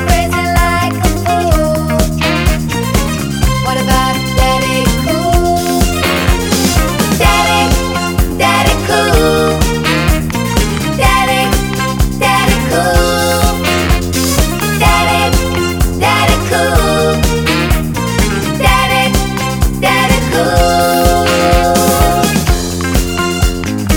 For Duet Disco 3:26 Buy £1.50